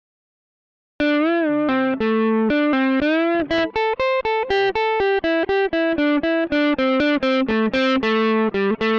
The first riff consists of some basic pentatonic scale guitar work in a minor scale.
Guitar riff based on the minor pentatonic scale
Minor Pentatonic Riff